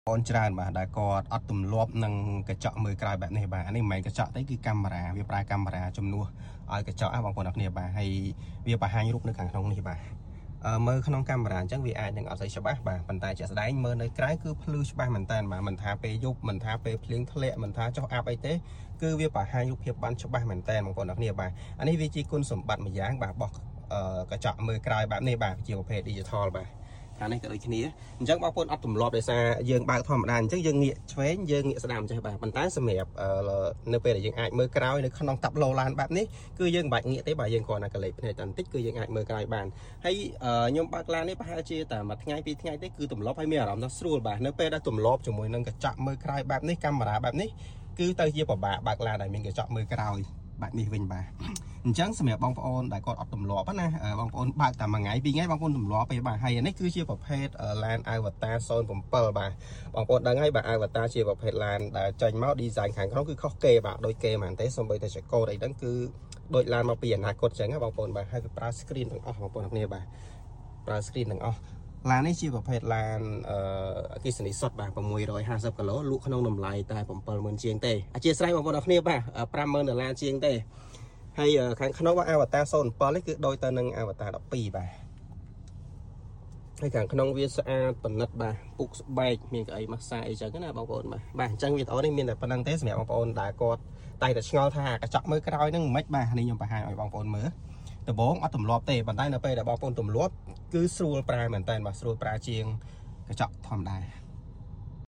Ultra HD Electronics exterior rearview Mirror sound effects free download